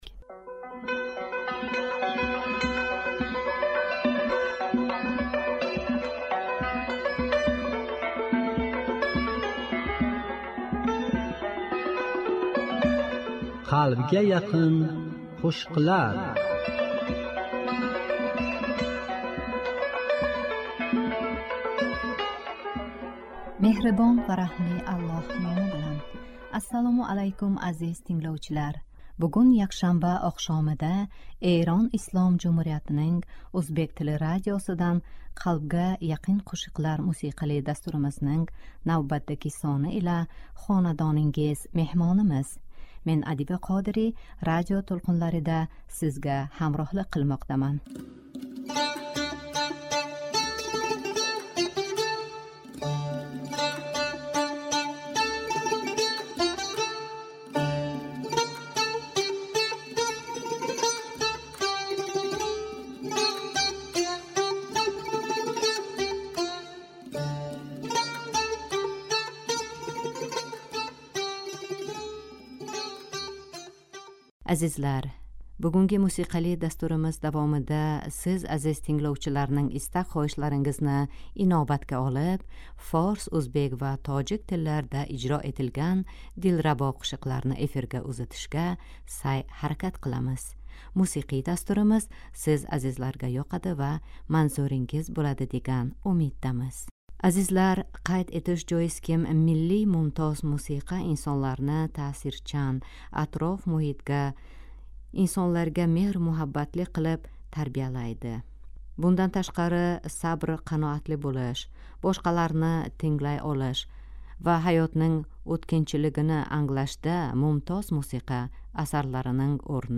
Азизлар, бугунги мусиқали дастуримиз давомида сиз азиз тингловчиларнинг истак -хоҳишларингизни инобатга олиб форс, ўзбек ва тожик тилларда ижро этилган дилрабо қўшиқларни эфирга ўзатишга сайъ-ҳаракат қиламиз.